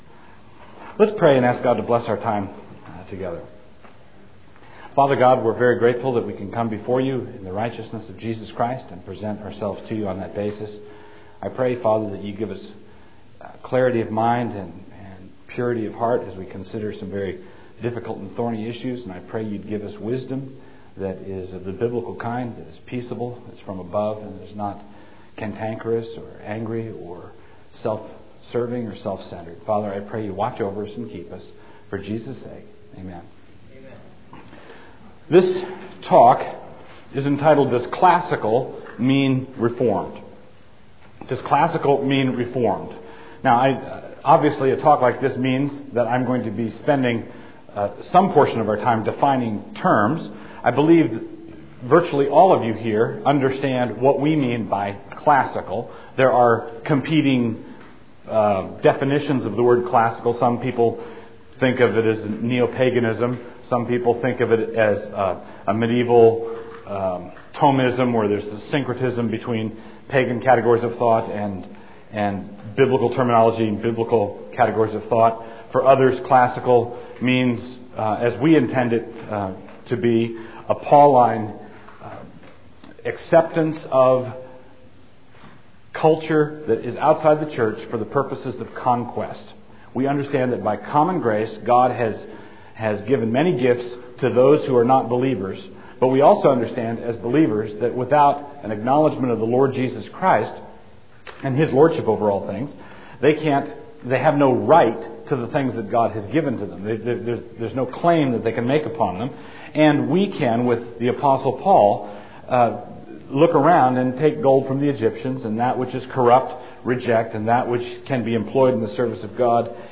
2000 Workshop Talk | 0:53:51 | Culture & Faith